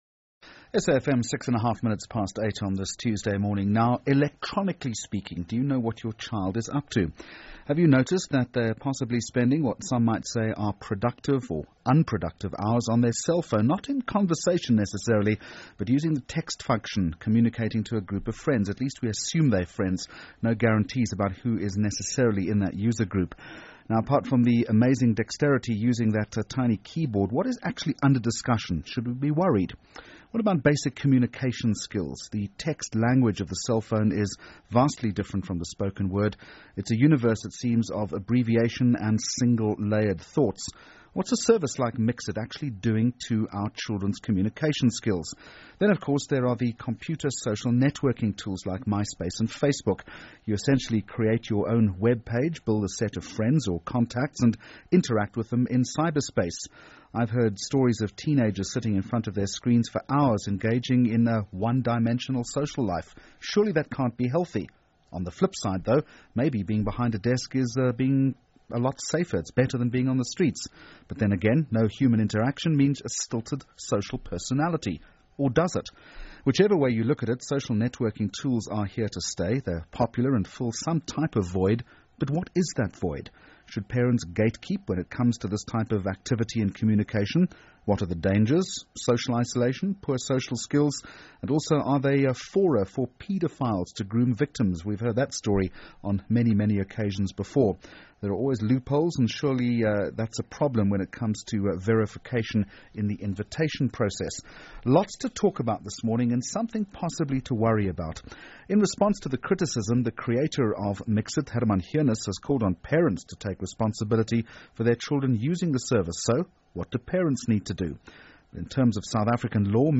Podcast - Jeremy Maggs interview on SAFM
The interview was for almost an hour on the After 8 Debate, a segment of the morning show on SAFM, hosted by Jeremy Maggs.